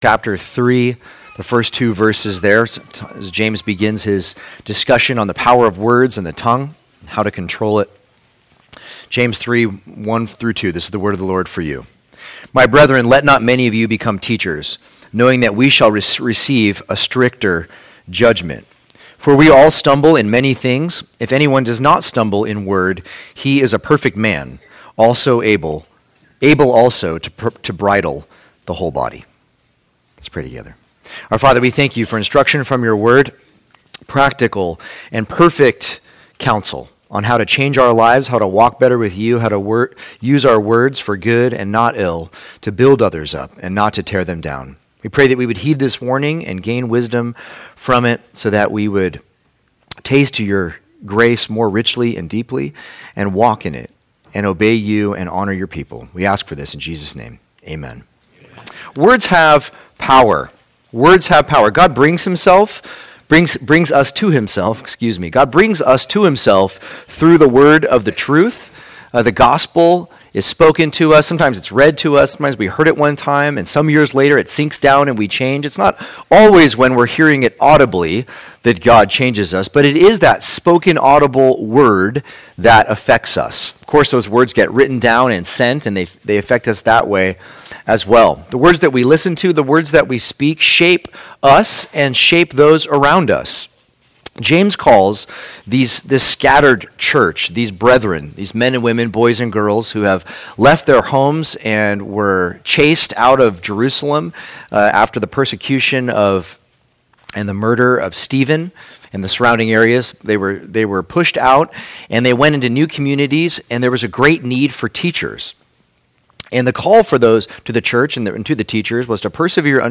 2024 Weighted Words Preacher